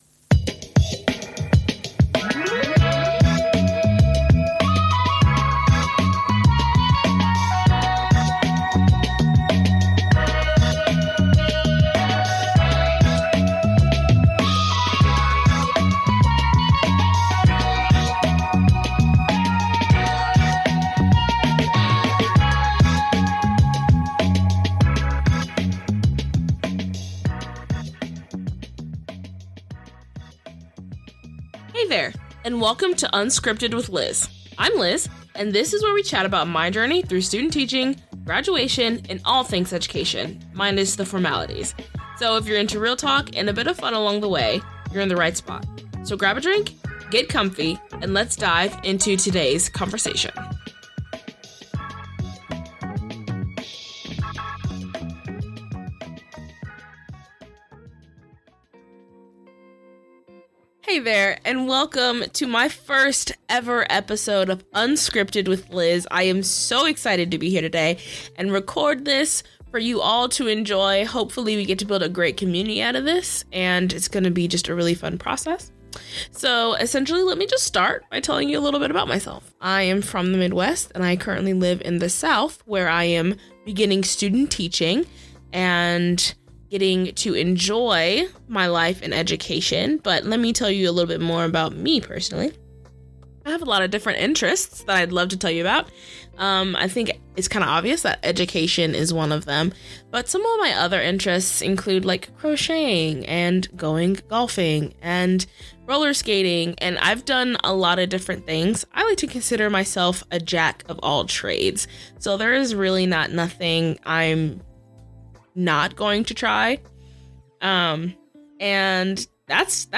Each episode offers a candid and conversational exploration of what it means to navigate academic and professional landscapes.